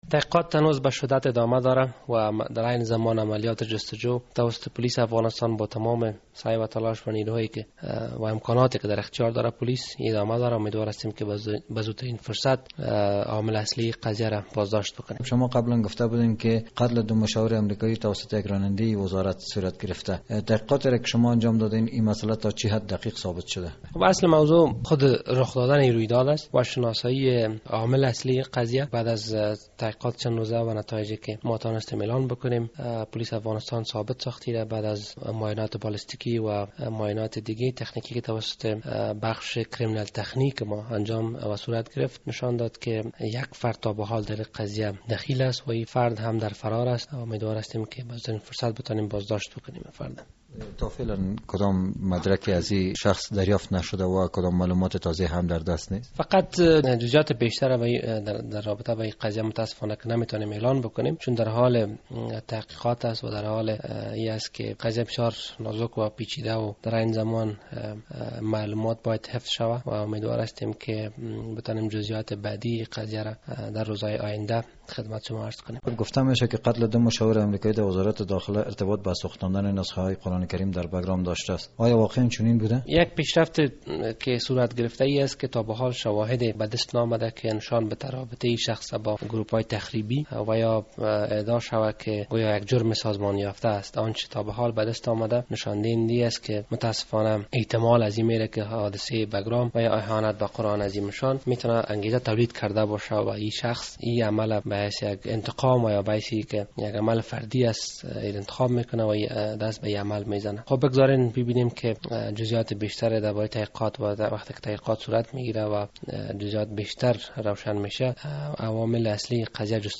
مصاحبه درمورد نتایج تحقیقات قتل دو مشاور امریکایی